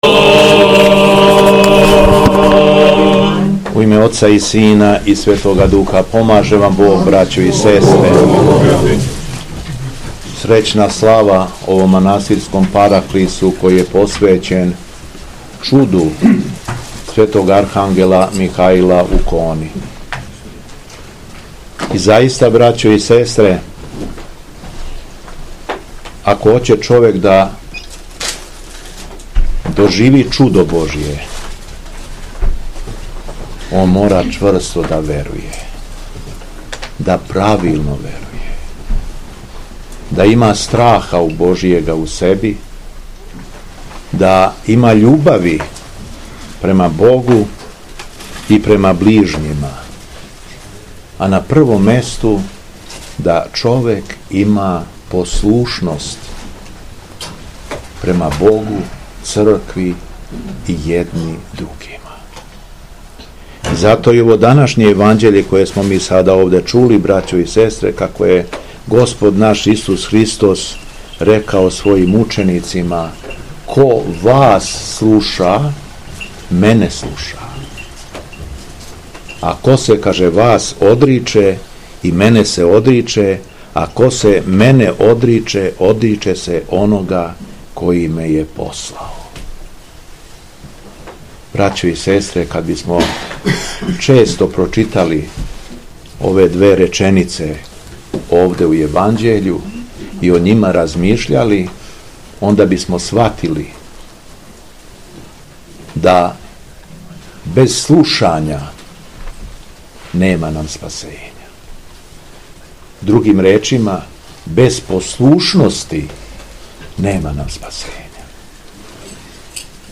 У четвртак, 19. септембра 2024. године, Његово Високопреосвештенство Митрополит шумадијски Господин Јован служио је Свету Архијерејску Литургију у капели манастира Благовештење, која је посвећена Чуду Светог Архангела Михаила у Хони.
Беседа Његовог Високопреосвештенства Митрополита шумадијског г. Јована
Након прочитаног јеванђелског зачала беседио је Митрополит Јован: